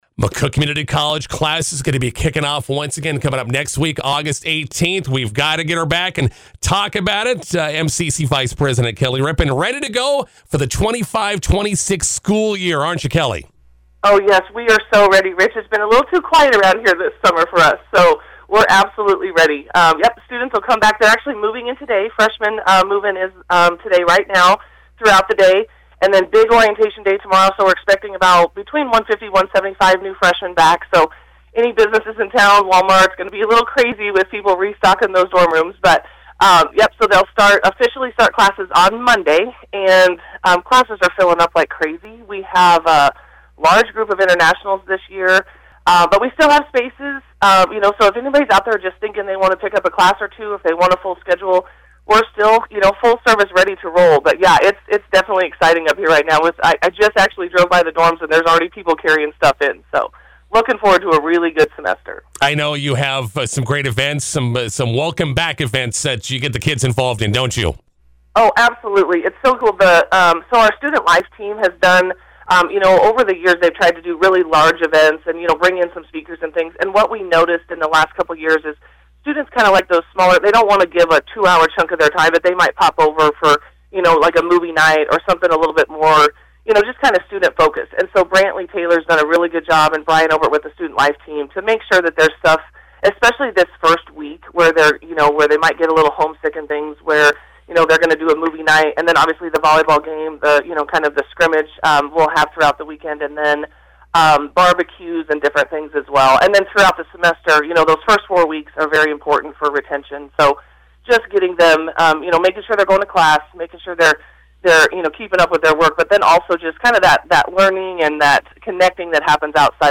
INTERVIEW: McCook Community College starts their 2025-26 school year on Monday.